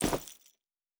Bag 14.wav